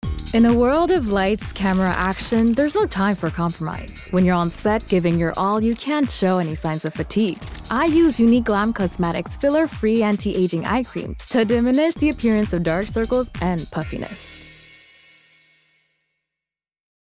Publicité (Glam Cosmetics) - ANG